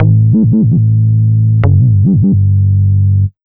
AMB147BASS-R.wav